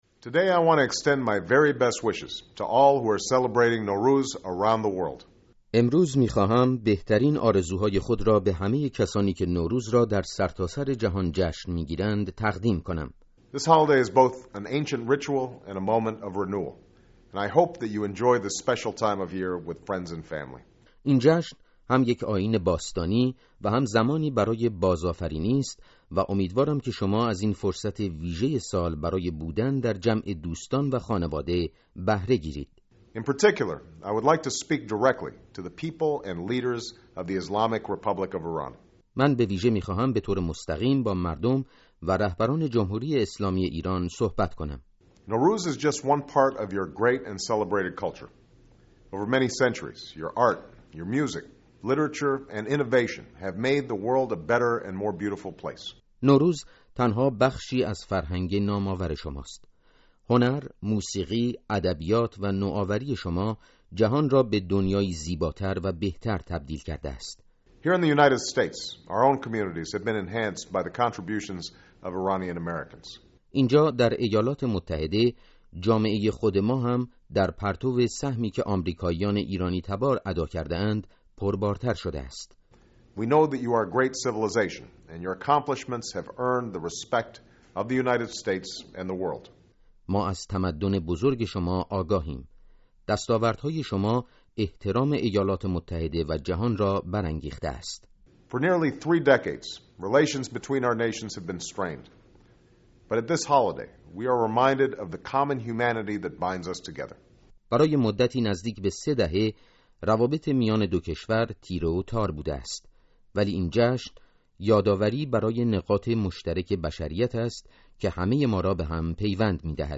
پیام نوروزی باراک اوباما، رئیس‌جمهوری ایالات متحده آمریکا